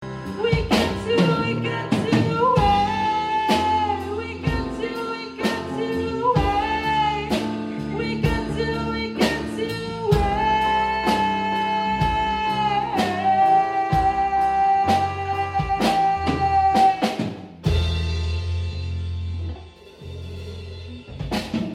con la batería y el bajo atrás